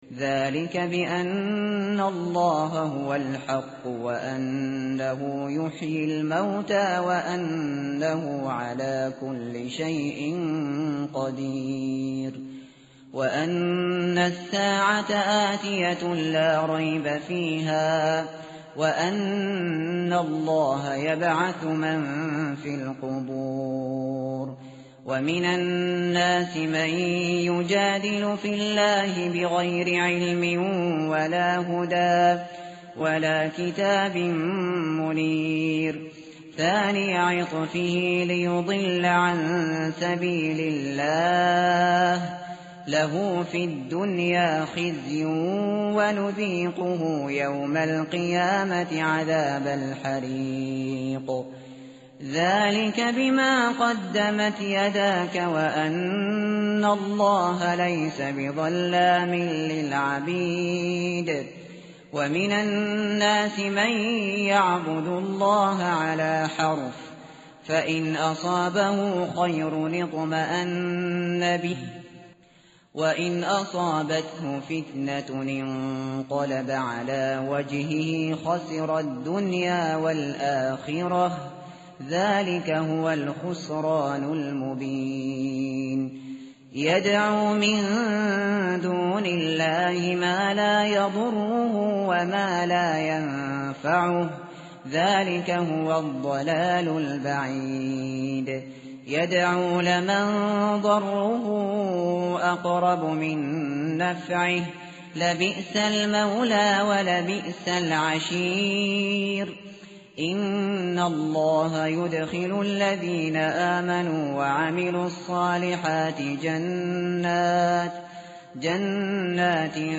tartil_shateri_page_333.mp3